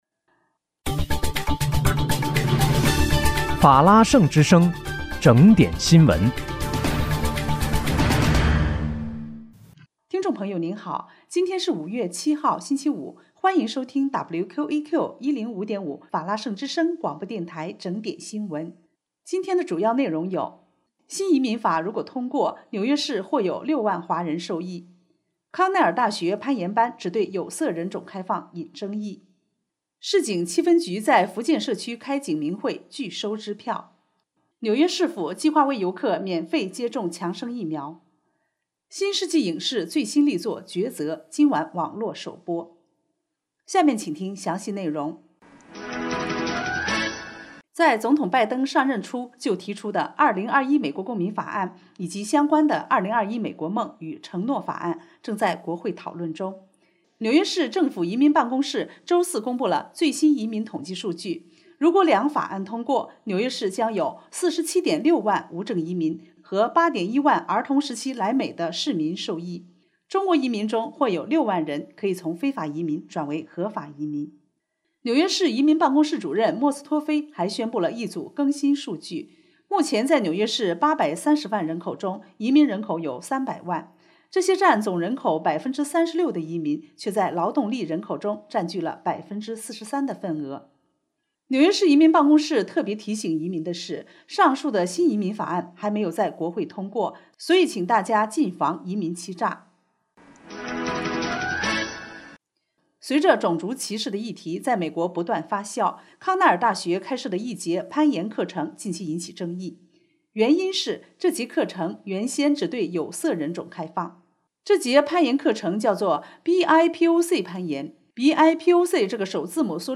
5月7日（星期五）纽约整点新闻